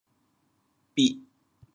反切 帮铁 调: 滴 国际音标 [pi?]